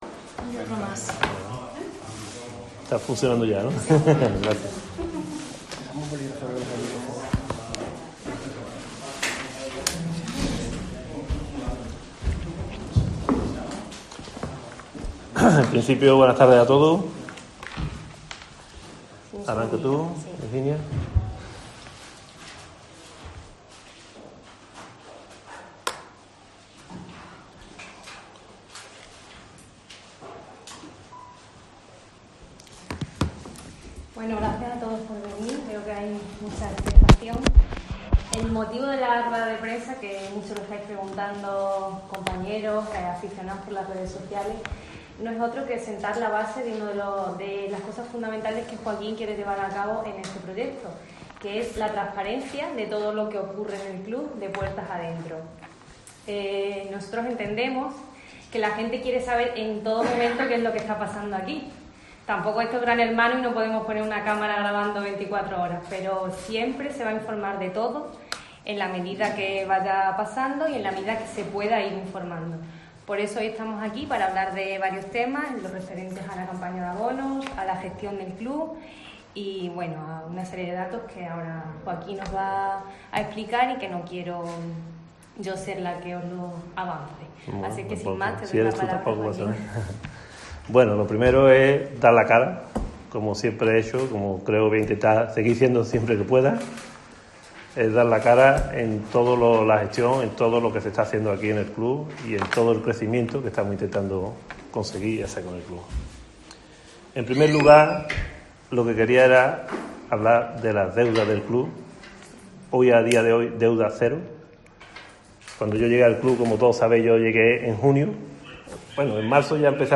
Rueda de Prensa